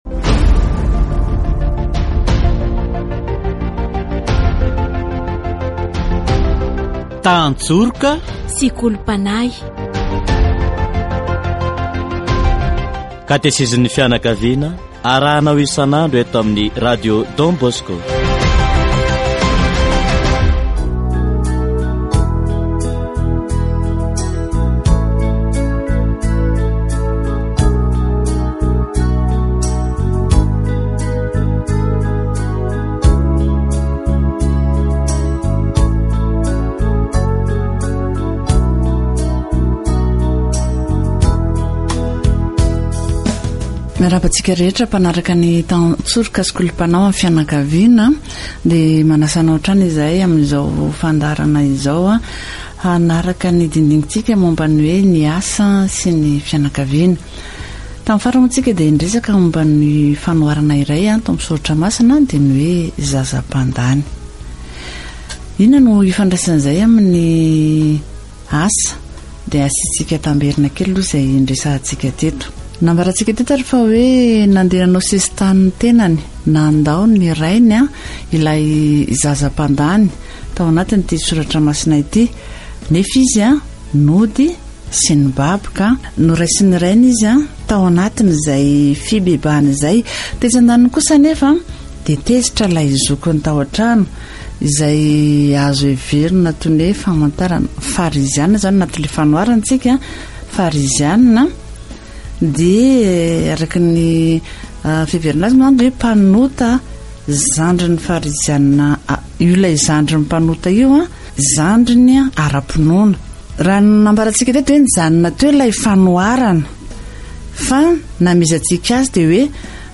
Katesizy momba ny asa